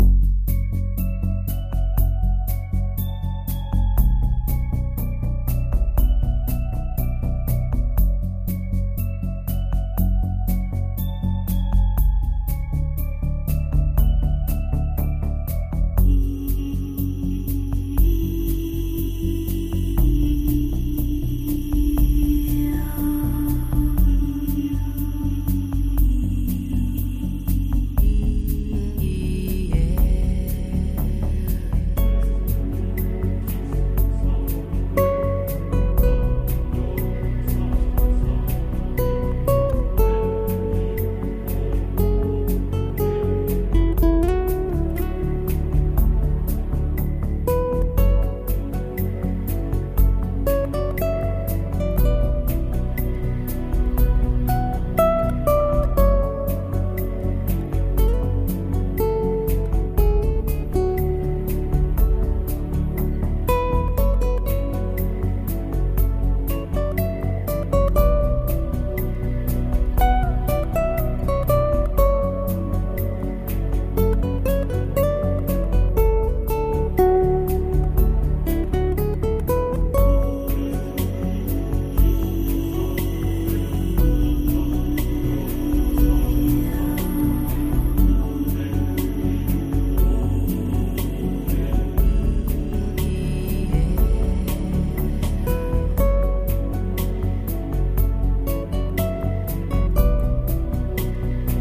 该唱片融入了诸多超凡脱俗的声音、并加入哥特式合唱团、吉他、鼓声等等形成了一个完整的多乐器音乐合集。